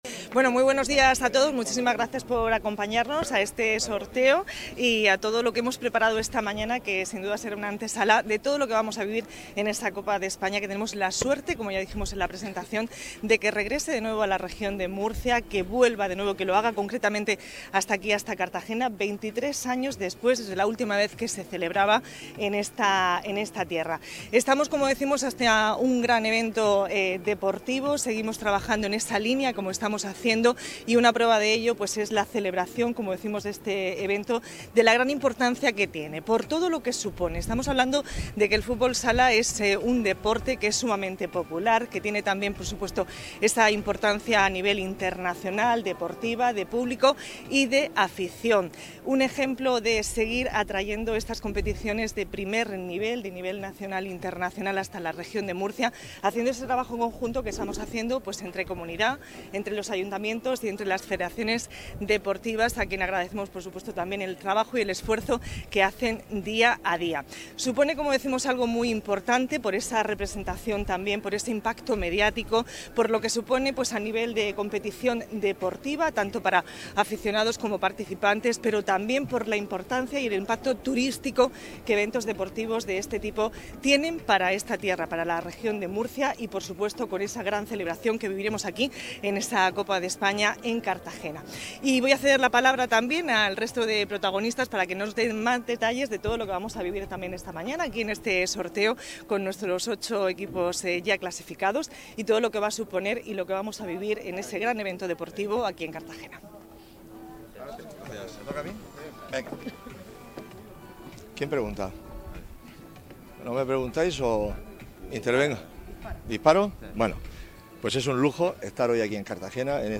Las cámaras de Teledeporte han retransmitido en directo el sorteo celebrado este jueves en el Museo del Teatro Romano en un acto en el que la alcaldesa Noelia Arroyo ha resaltado la capacidad de Cartagena para ser un destino turístico de primer nivel gracias a la simbiosis entre cultura y deporte
La Copa de España de fútbol sala celebrará la próxima edición en Cartagena del 21 al 24 de marzo con un derbi regional en cuartos de final entre el equipo anfitrión, el Jimbee, y su eterno rival, ElPozo Murcia. El sorteo de los emparejamientos se ha celebrado este jueves, 14 de diciembre, en el Museo del Teatro Romano de Cartagena.